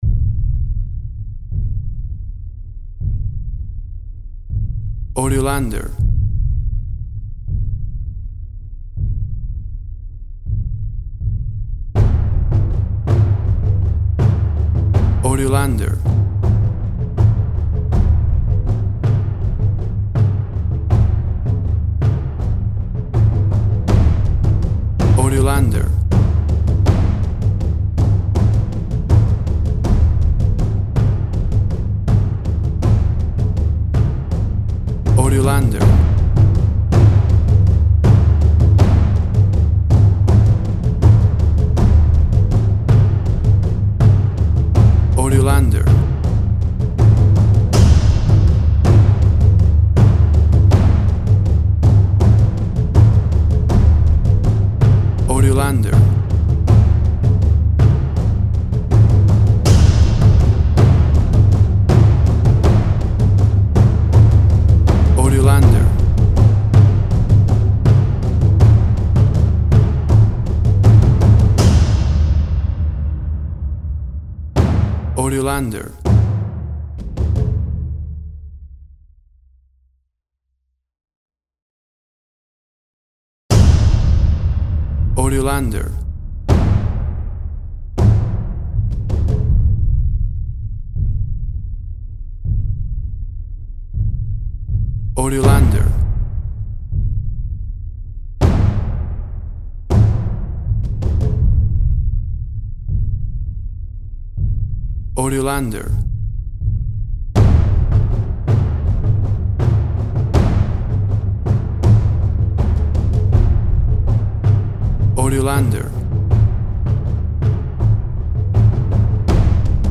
Exotic and world music!
Tempo (BPM) 80